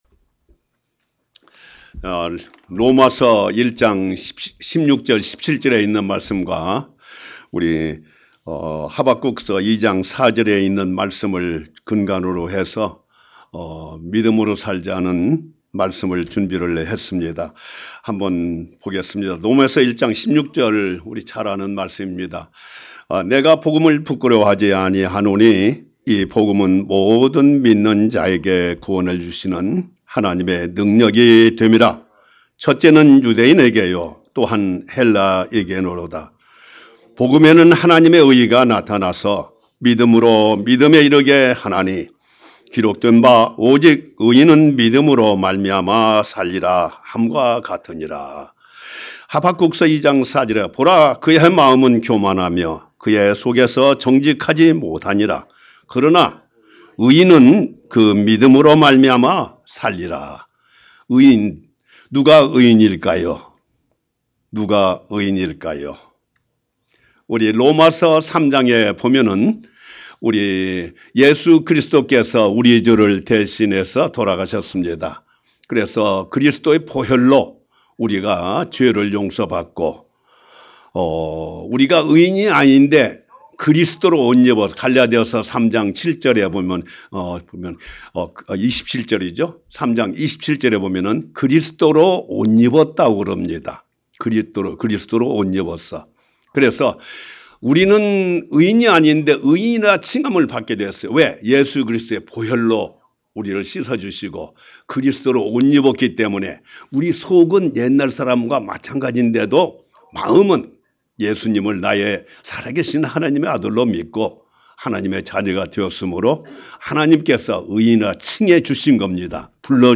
Sermon - 믿음으로 살자 Let’s live by faith.